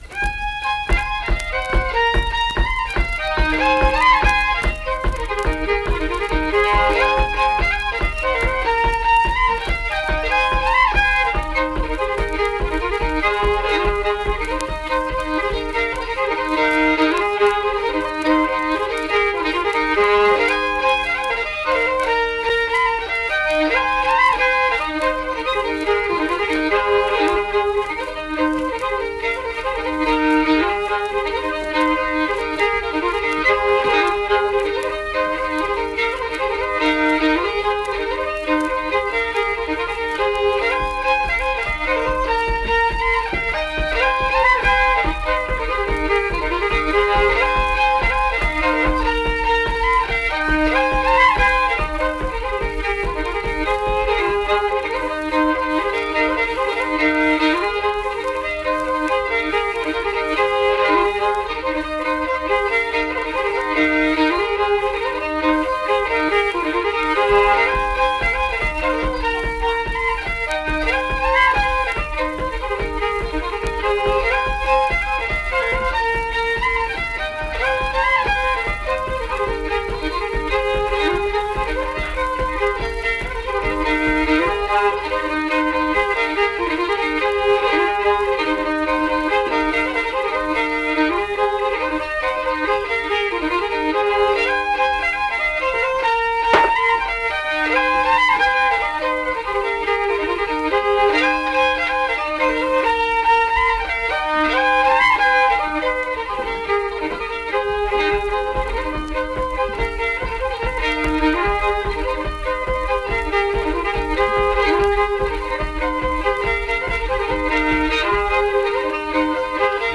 Unaccompanied fiddle music
Instrumental Music
Fiddle